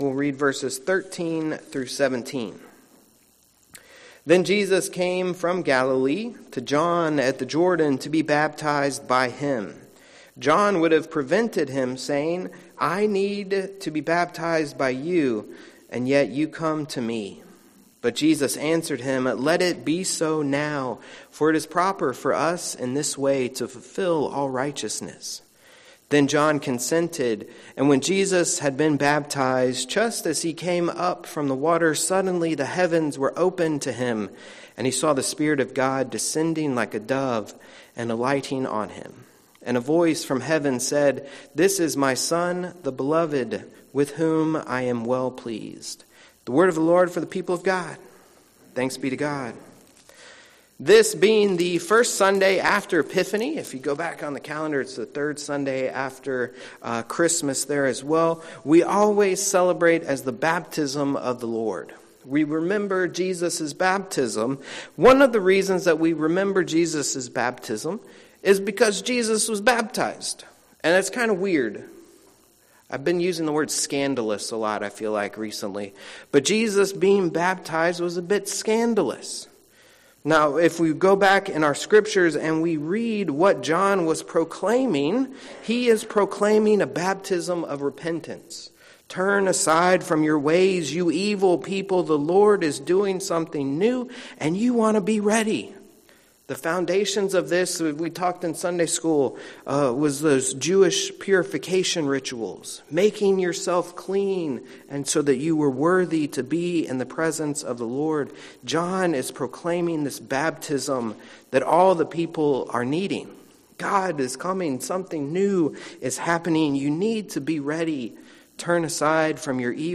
Listen to Sermons Tagged: Sermons